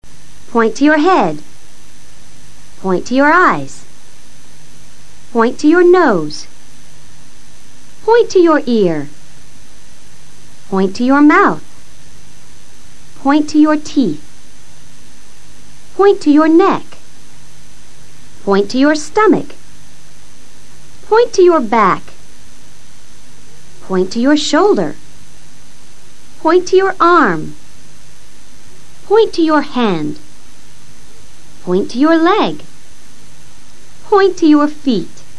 La profesora mencionará diferentes partes del cuerpo.